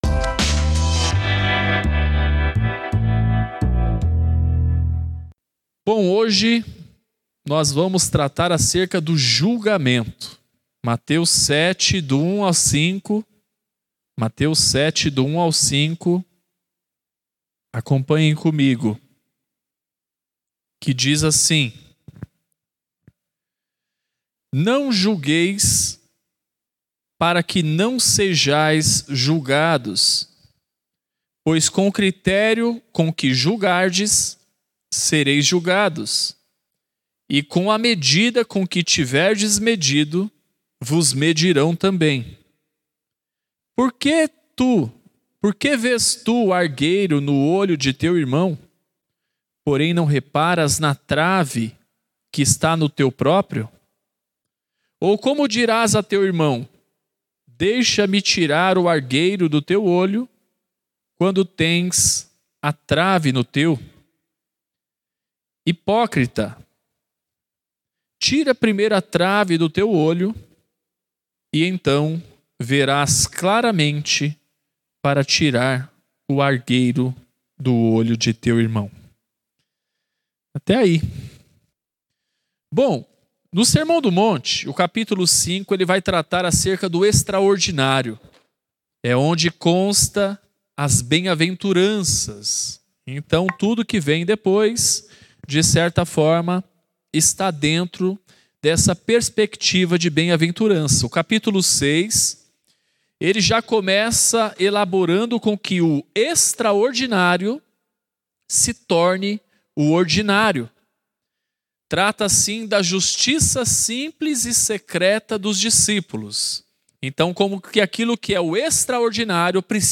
Mensagem realizada no Culto de Reflexão de Oração.